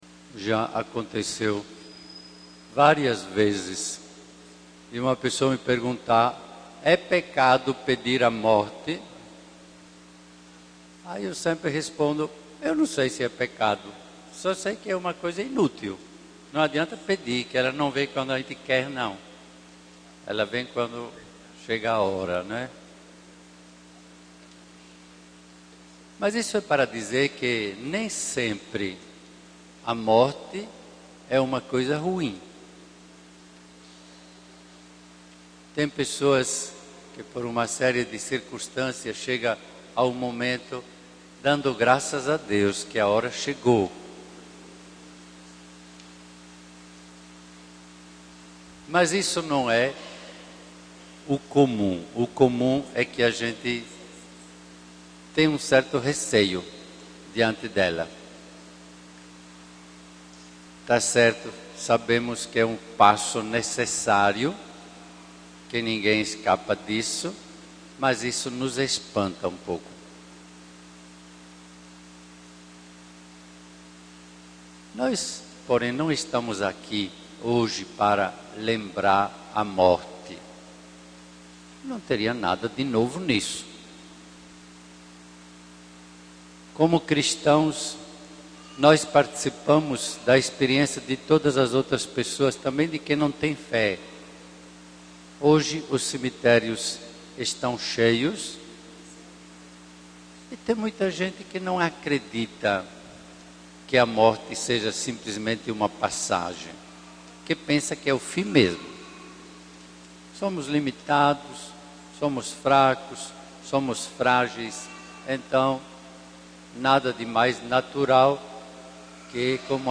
A primeira missa inclusive já aconteceu e foi presidida pelo Bispo Diocesano Do Egídio Bisol. Ele falou do sentimento que toma essa data tradicional para os cristãos católicos, fazendo uma relação entre a ausência de um ente querido e a Palavra de Deus.